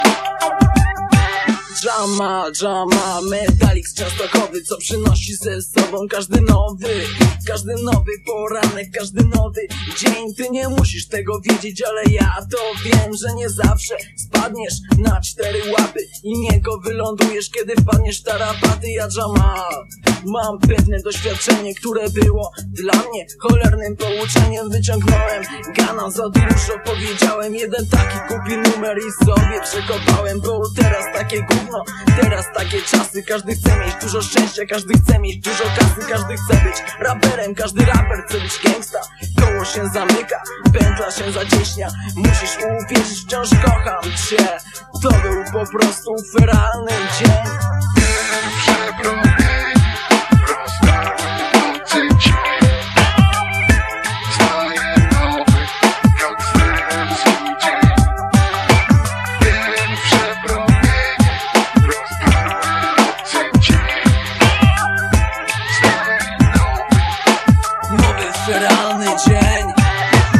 piosenka z kasety lata 90 - Hip-hop / Rap / R'N'B
piosenka z kasety lata 90